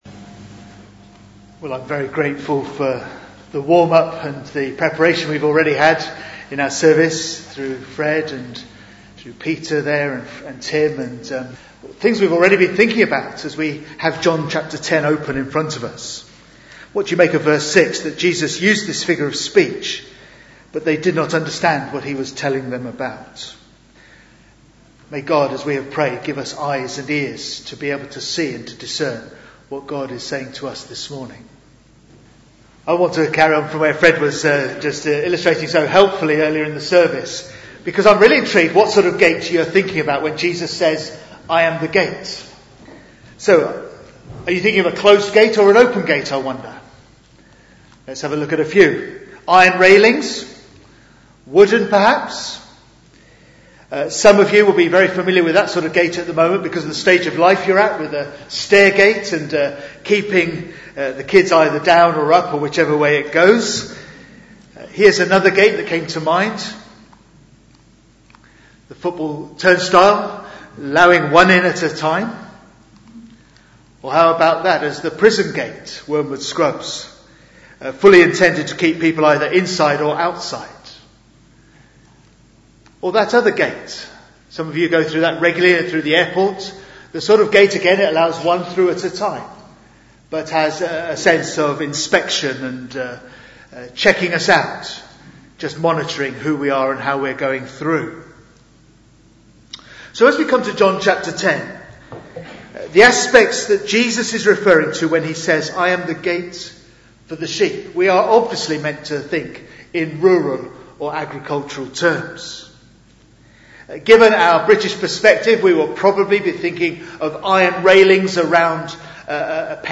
Bible Text: John 10:1-10 | Preacher